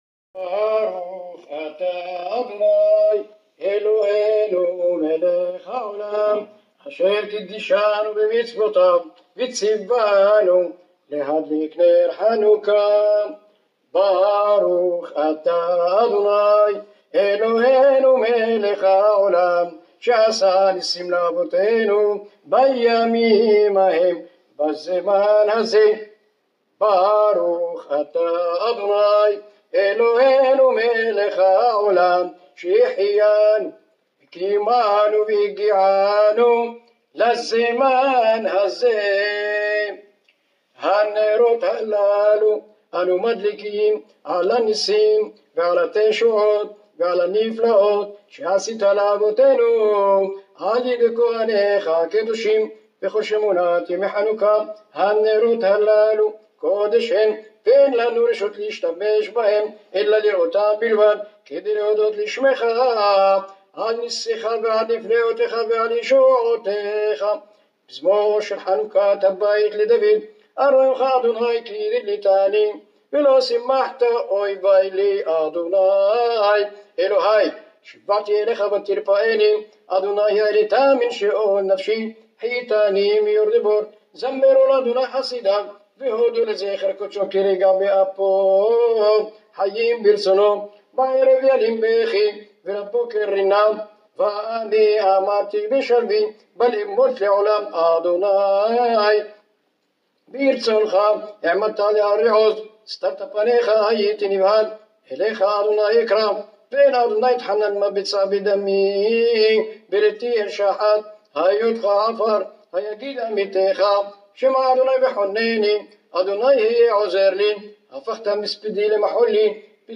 שלא נשכח את מנגינת הברכה ששרו אבותינו הקדושים